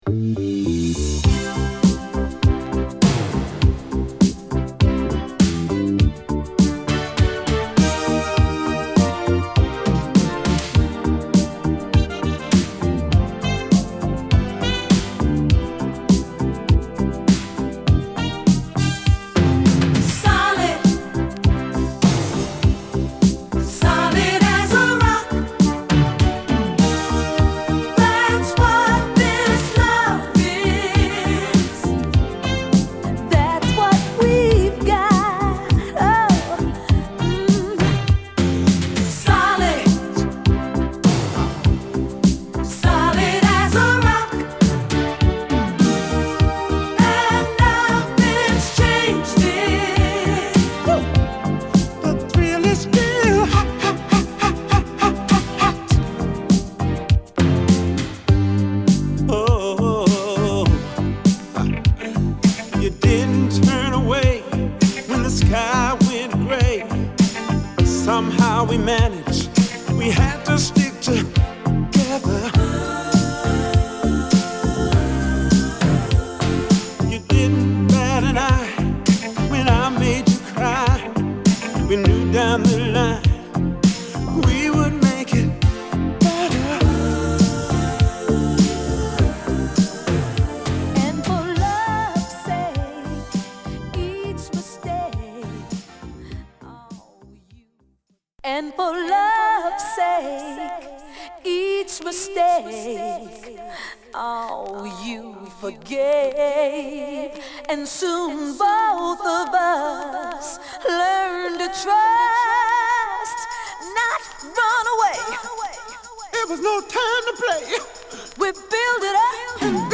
BPM100位のミッドブギーソウル！